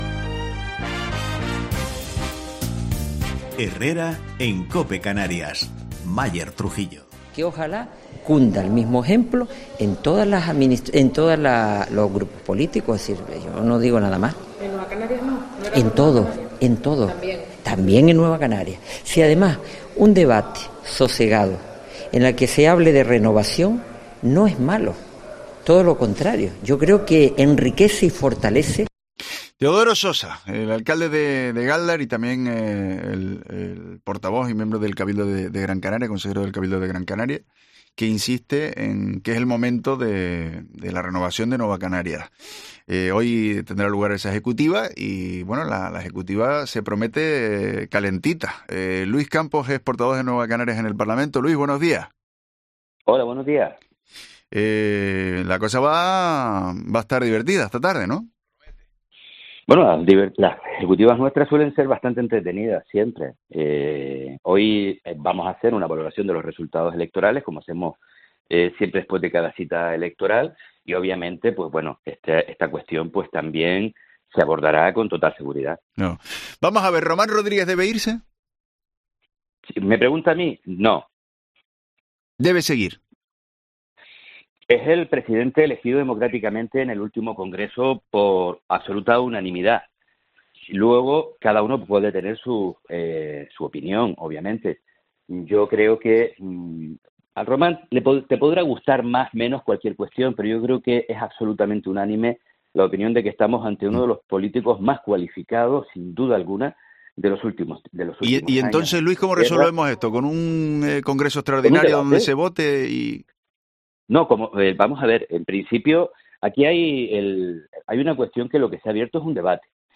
Entrevista a Luis Campos, diputado Nueva Canarias en el Parlamento de Canarias